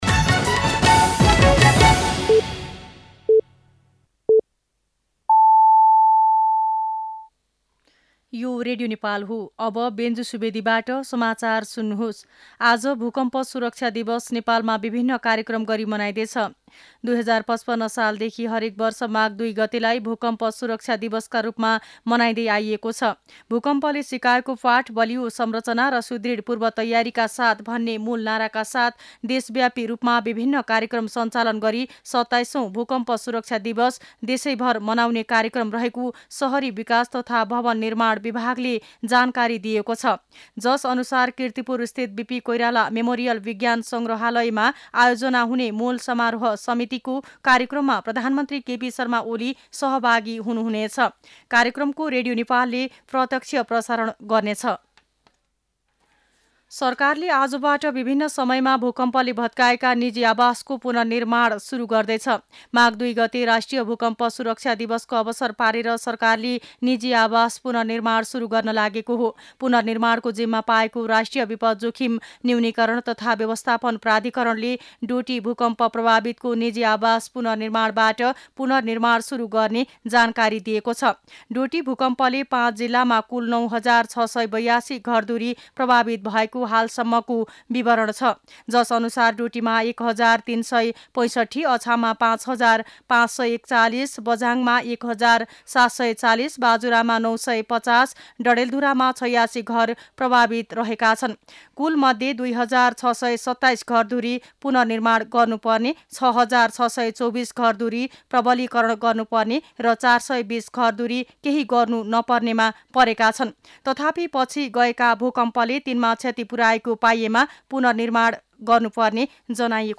मध्यान्ह १२ बजेको नेपाली समाचार : ३ माघ , २०८१